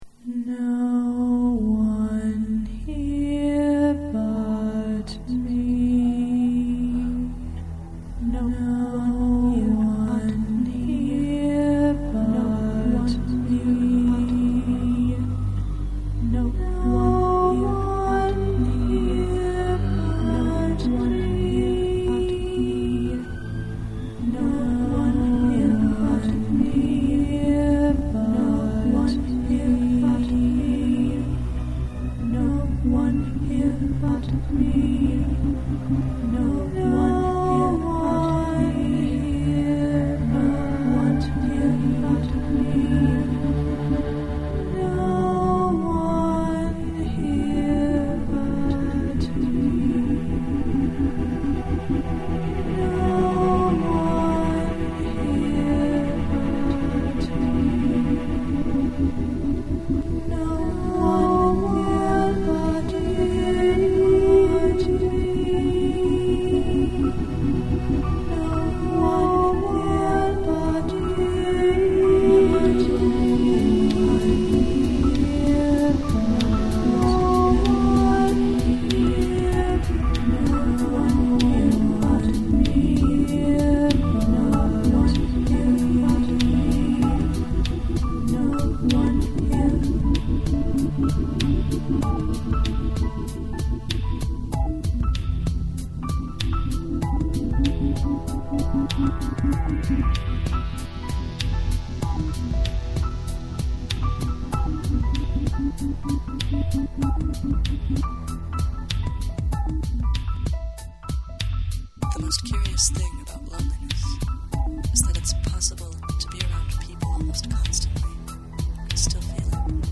I make electronic music.